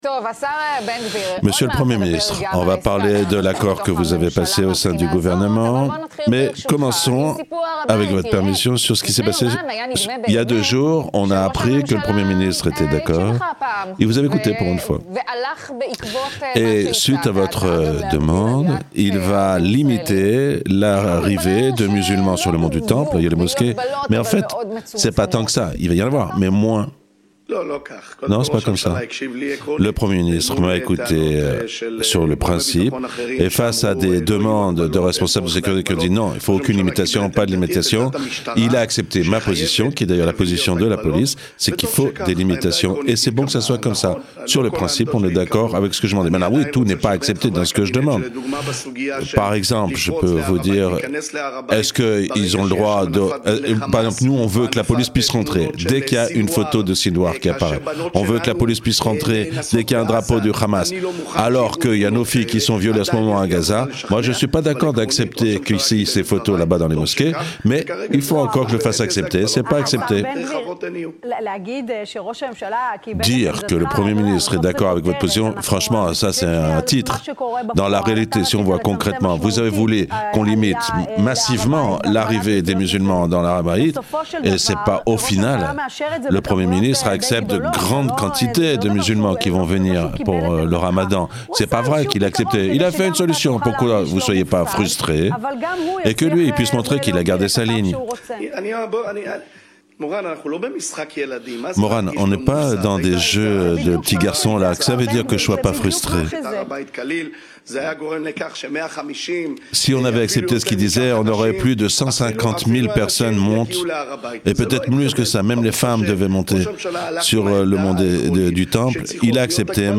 Interview d'Itamar BenGvir traduite en français